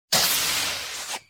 airlock.ogg